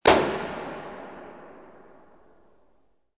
Descarga de Sonidos mp3 Gratis: disparo 16.
descargar sonido mp3 disparo 16